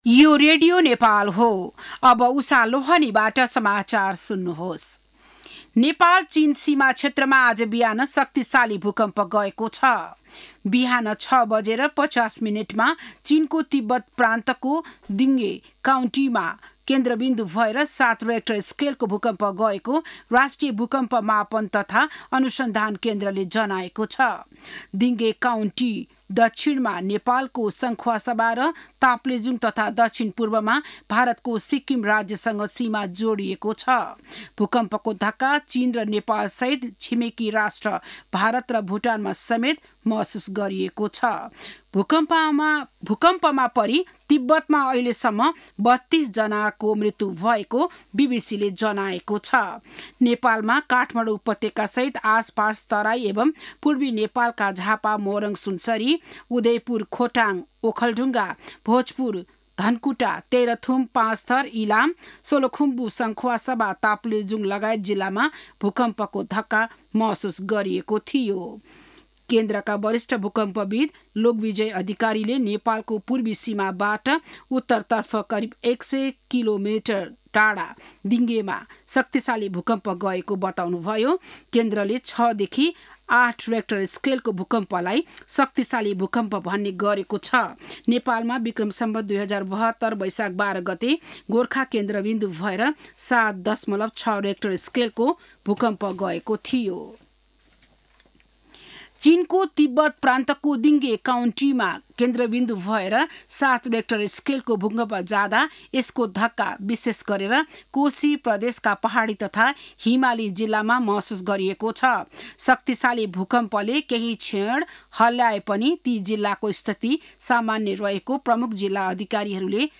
An online outlet of Nepal's national radio broadcaster
बिहान ११ बजेको नेपाली समाचार : २४ पुष , २०८१
11-am-news-1-1.mp3